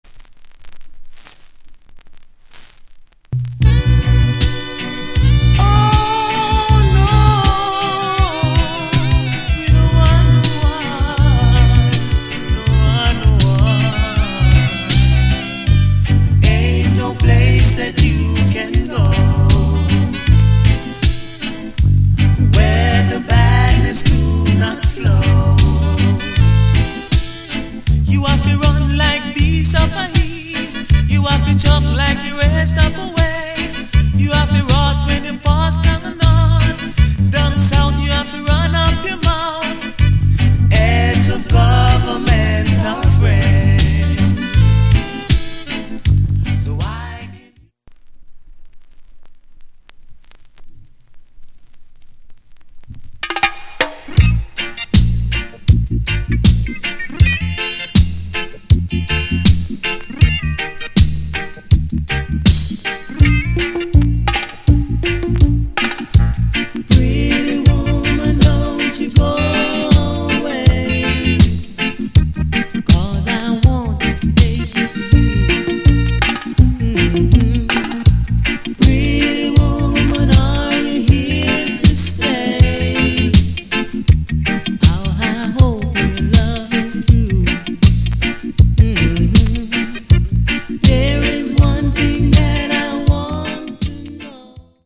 * Early 80's Good Harmony! Mellow名曲!!